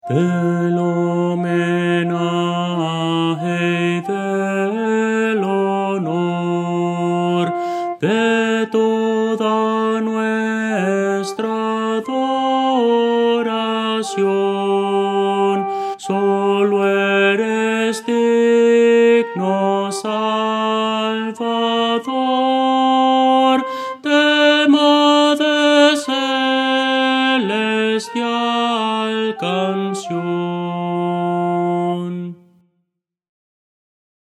Voces para coro
Soprano – Descargar
Audio: MIDI